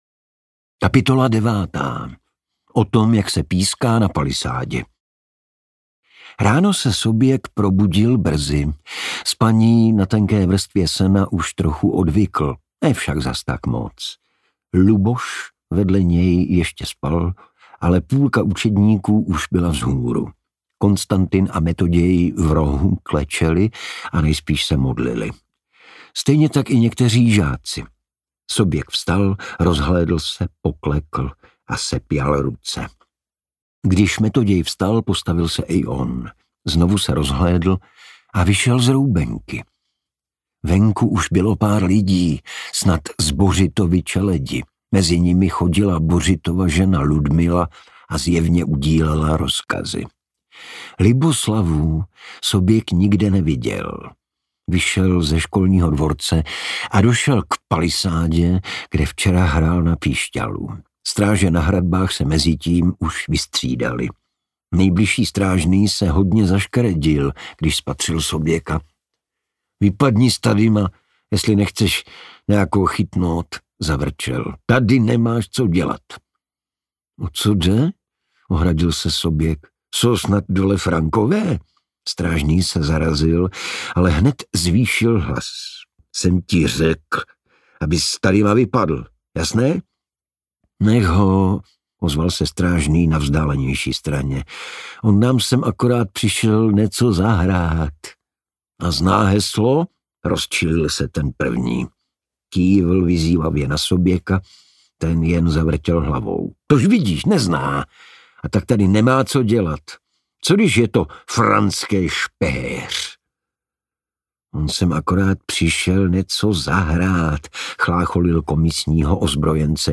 Poslechněte si kapitolu 9 z audioknihy, jak ji načetl Igor Bareš.